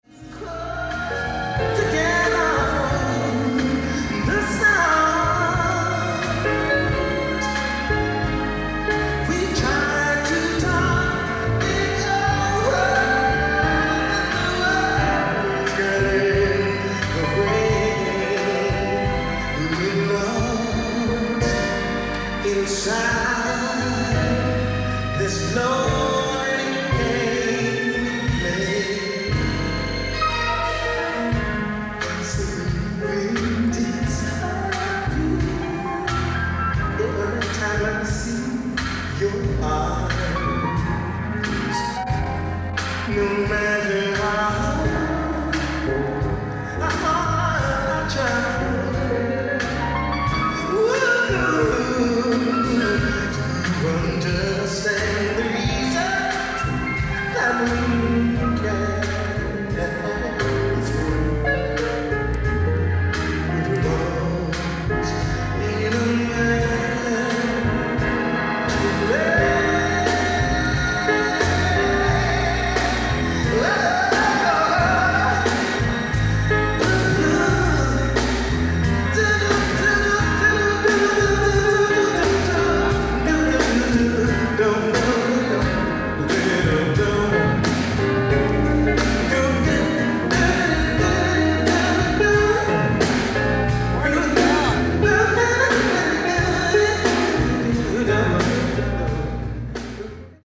ライブ・アット・リリック・オペラハウス、ボルチモア 11/27/1987
※試聴用に実際より音質を落としています。